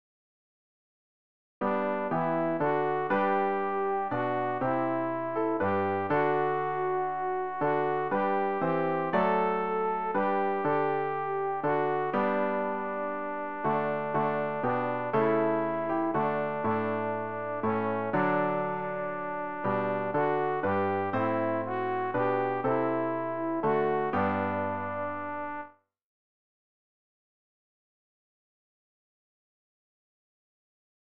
rg-791-o-jesu-christe-alt.mp3